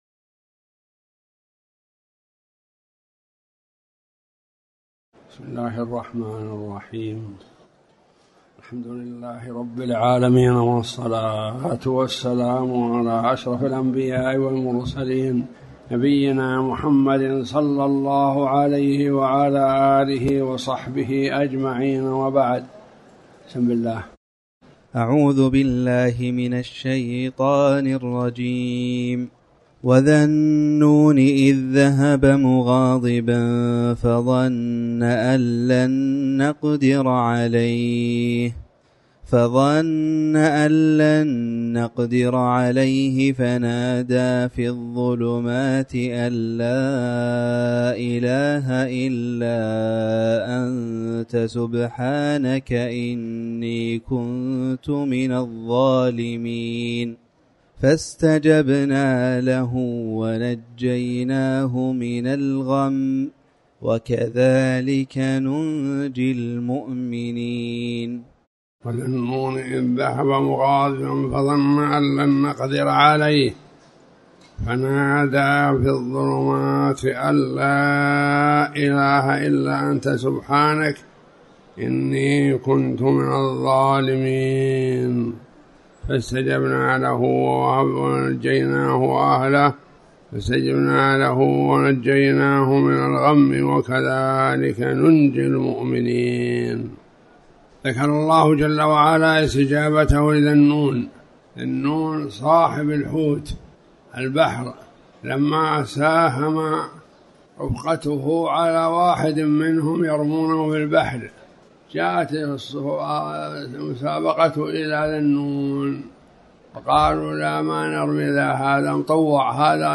تاريخ النشر ٥ شعبان ١٤٤٠ هـ المكان: المسجد الحرام الشيخ